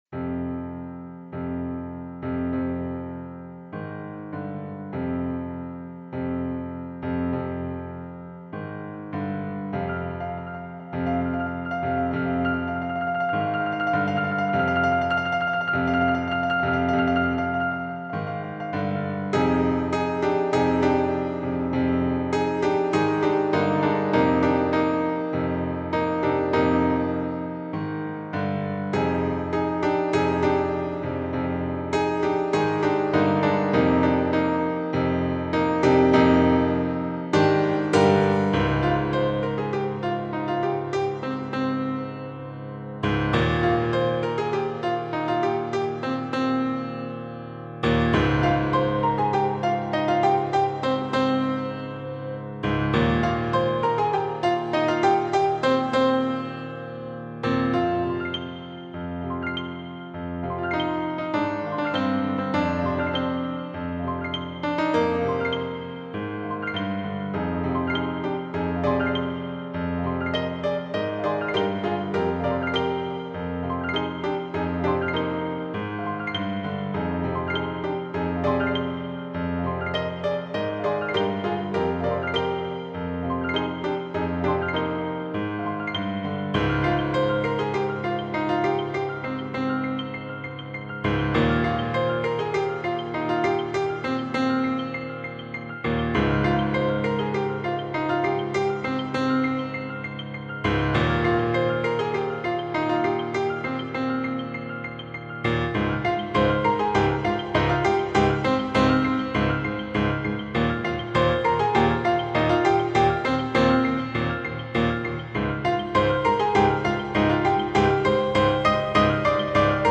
歩む先に立ちこめる怪しい雲、嵐の前の静けさ…。怪しげな雰囲気の曲。